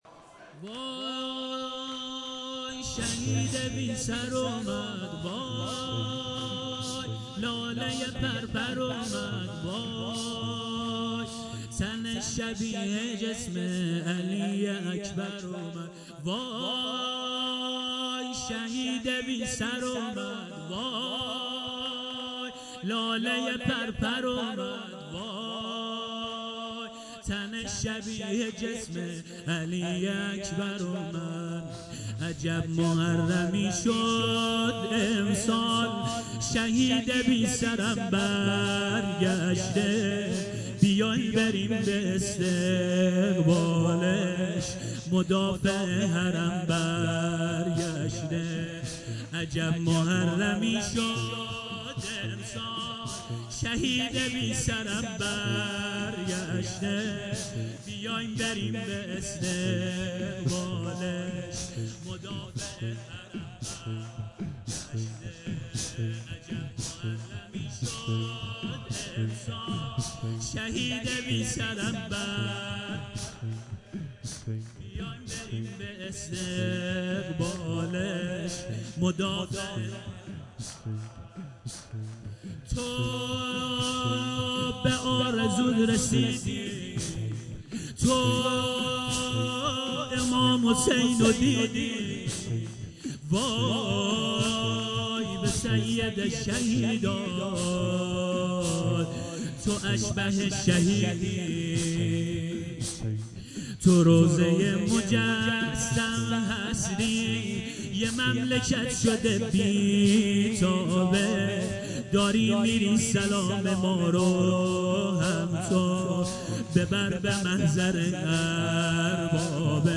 زمینه - وای شهید بی سر اومده وای لاله پرپر اومد